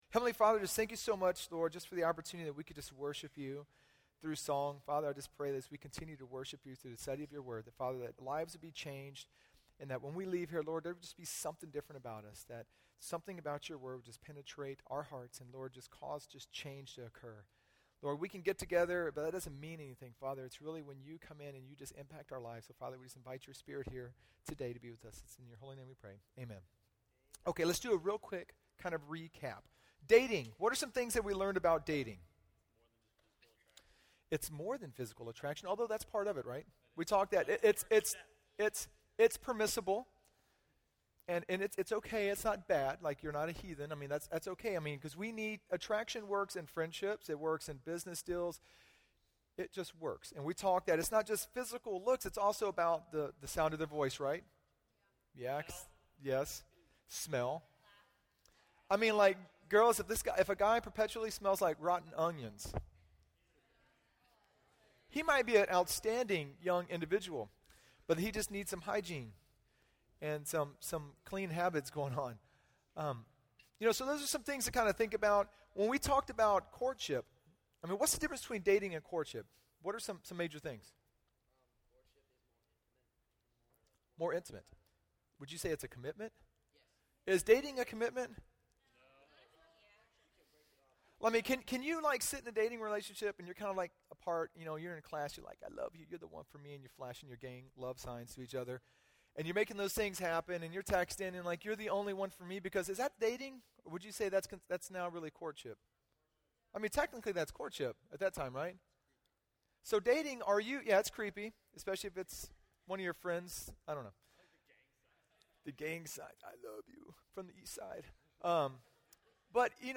Â We covered dating, courtship and finally marriage. Â Here is the link to the teaching from 1 Peter 3:1-7.